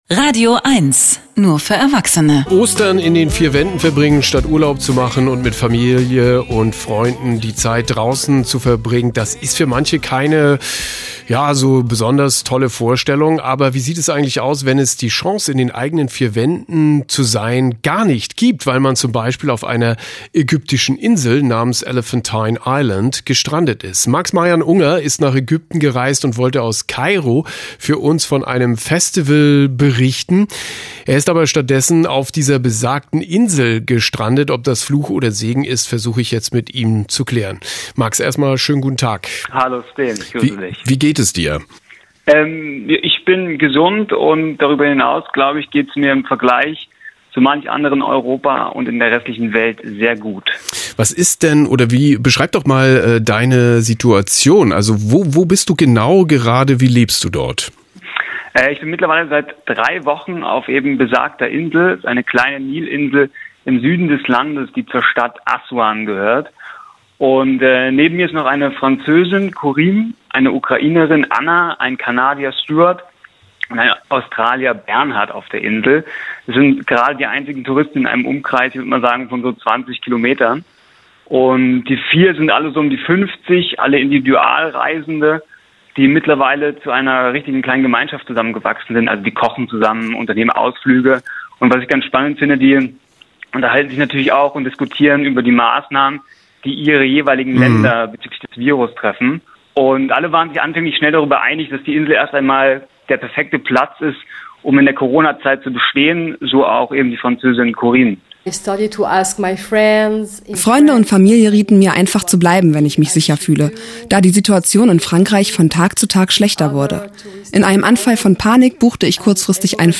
Interview (Online bis 08.05.2020)